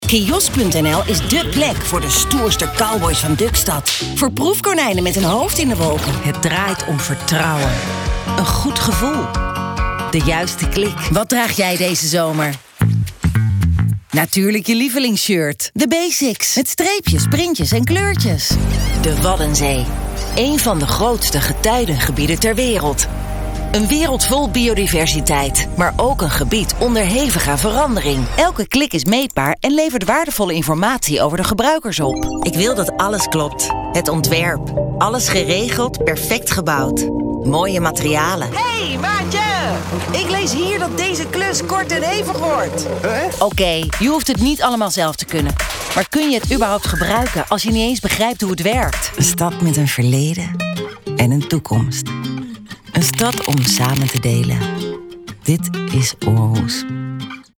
voice over artist, uit het hart
Authentiek, fris, een tikkie stoer, energiek, warm.